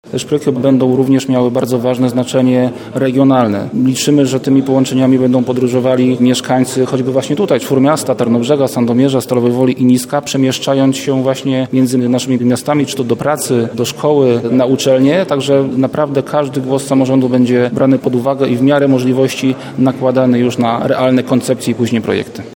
W rozmowie kuluarowej z dziennikarzami wiceminister Rafał Weber odniósł się między innymi do propozycji Czwórmiasta, aby uwzględnić Tarnobrzeg i Sandomierz na trasie planowanej budowy linii kolejowej prowadzącej do Centralnego Portu Komunikacyjnego.